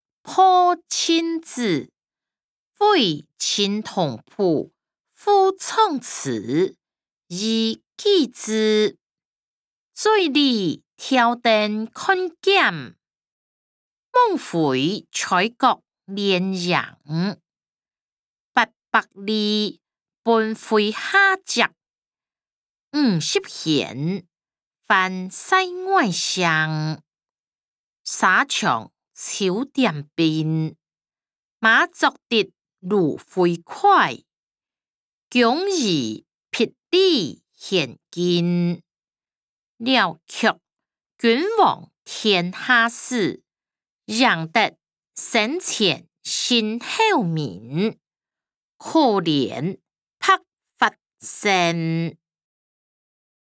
詞、曲-破陣子•為陳同甫賦壯詞以寄之音檔(大埔腔)